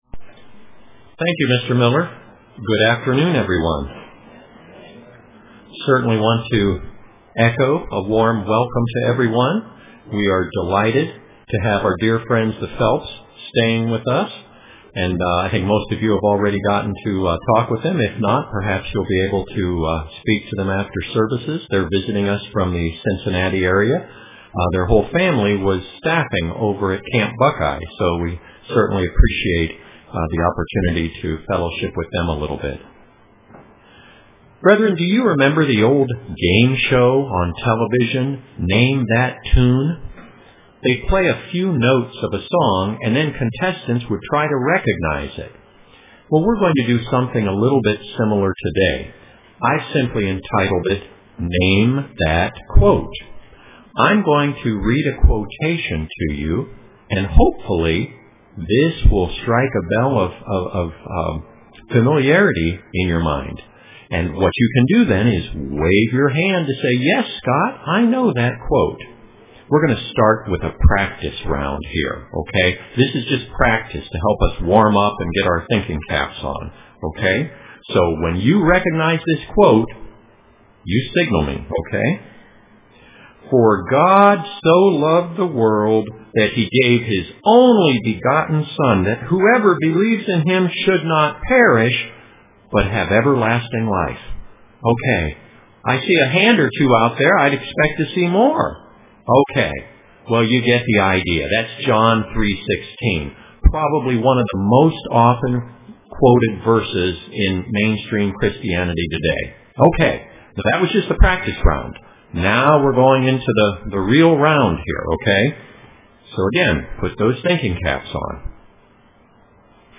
Print Our Example is Noticed UCG Sermon Studying the bible?